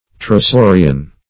Search Result for " pterosaurian" : The Collaborative International Dictionary of English v.0.48: Pterosaurian \Pter`o*sau"ri*an\, a. (Paleon.)
pterosaurian.mp3